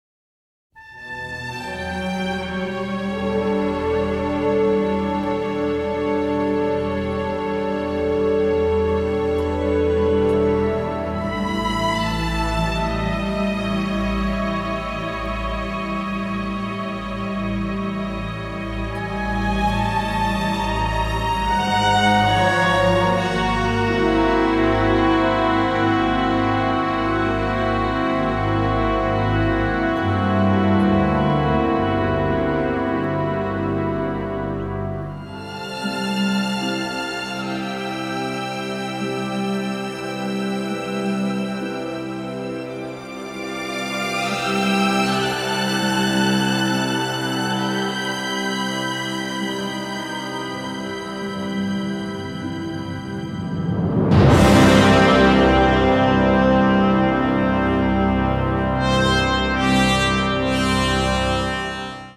ORCHESTRA SUITE: